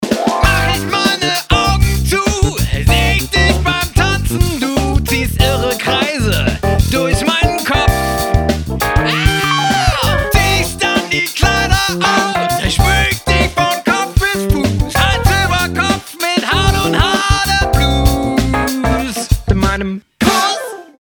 turntables
bass
drums
voc, keys, flugelhorn
Polyrhythmisch, asymmetrisch und paradox: